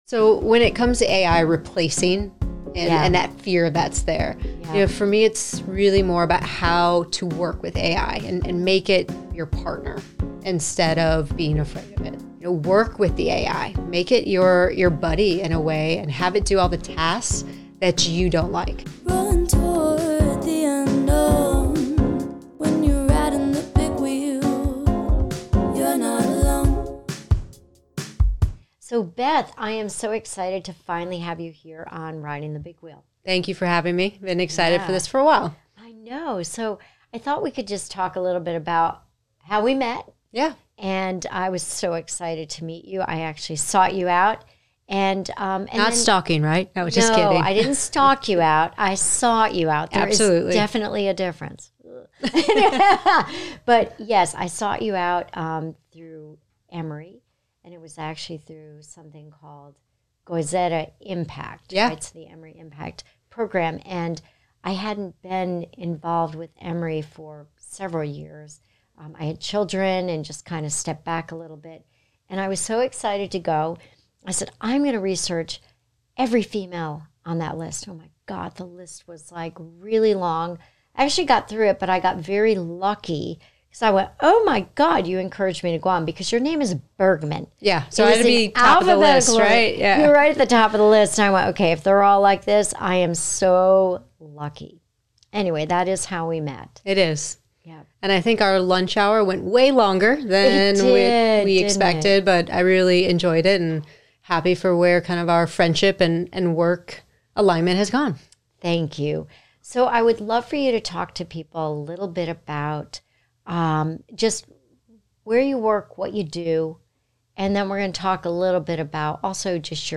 You'll learn: How to adapt to AI without feeling overwhelmed Real-life applications of AI you can start using today Insights on how AI is reshaping industries, communication, and daily tasks Whether you're curious about AI or looking for actionable tips, this conversation offers easy-to-understand insights for anyone ready to embrace the future.